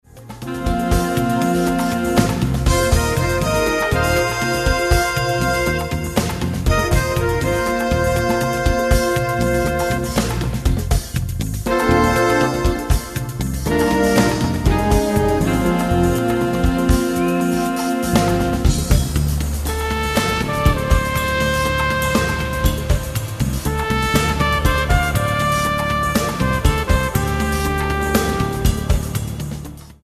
Genre: Jazz
keyboards
tenor sax
rhythm guitar
lead guitar
drums
percussion
trumpet, cornet
electric and acoustic bass